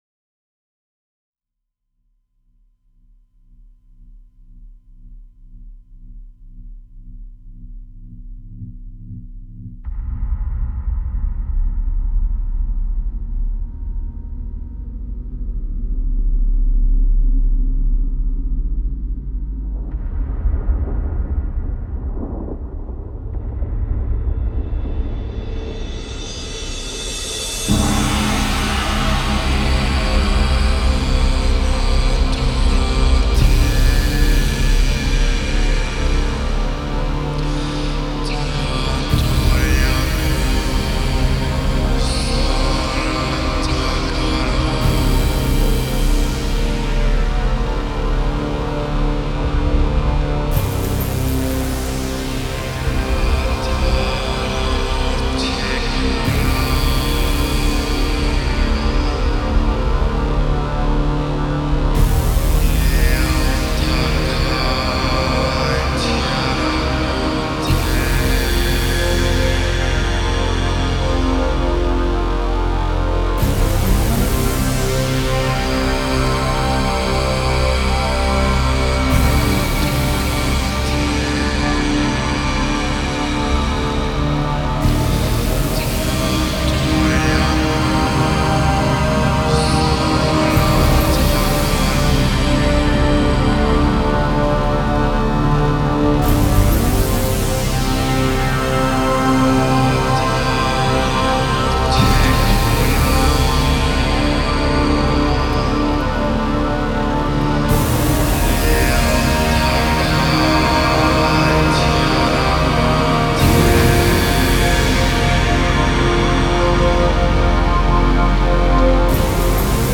pure sensitive electronic music
Grinding sounds straight out from the boring hell of europe.